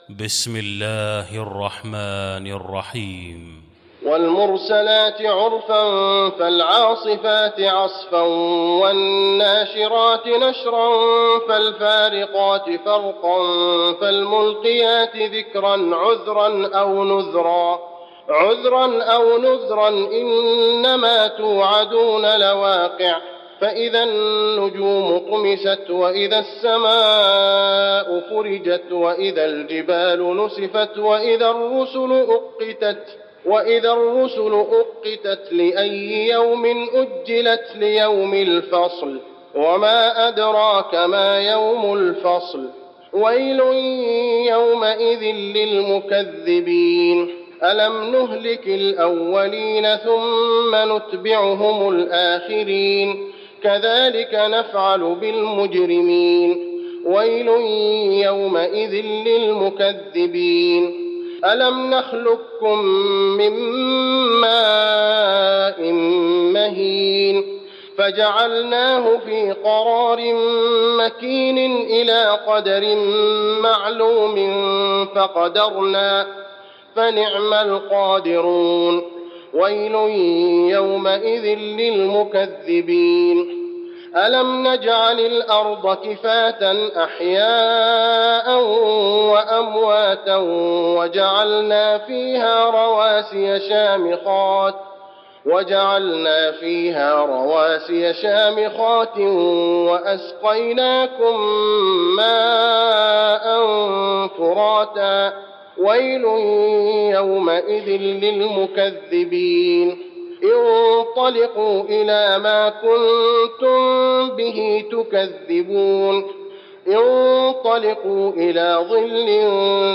المكان: المسجد النبوي المرسلات